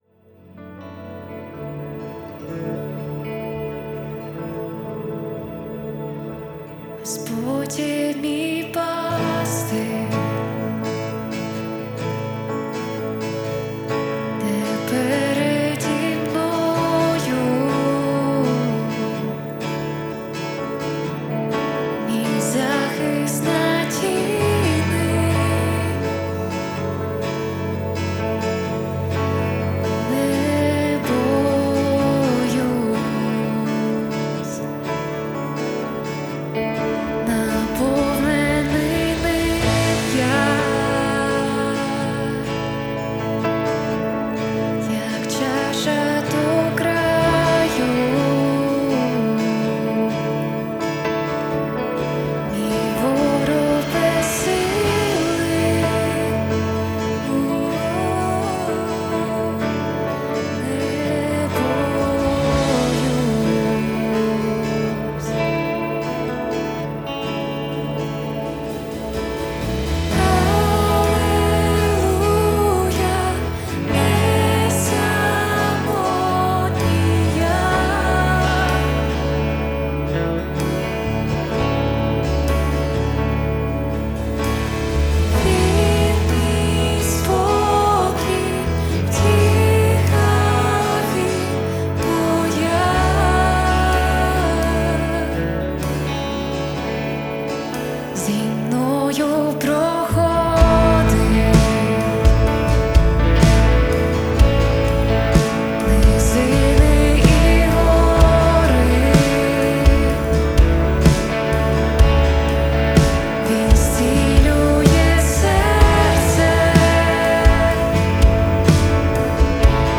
159 просмотров 13 прослушиваний 1 скачиваний BPM: 126